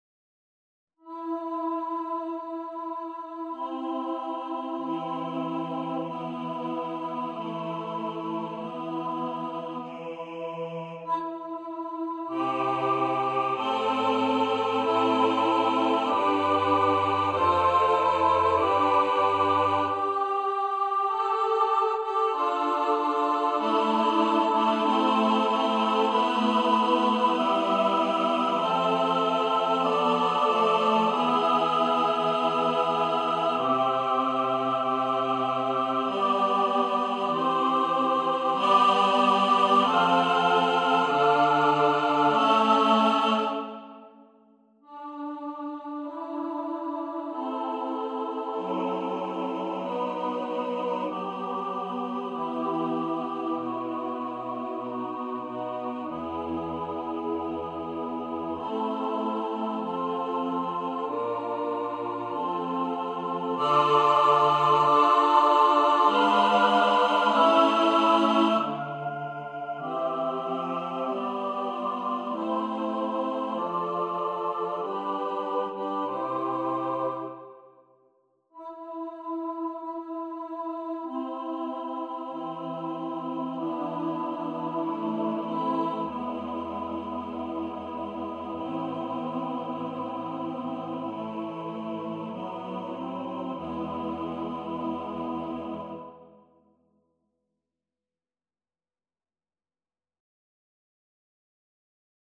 Free Sheet music for Choir (SATB)
Choir  (View more Easy Choir Music)
Classical (View more Classical Choir Music)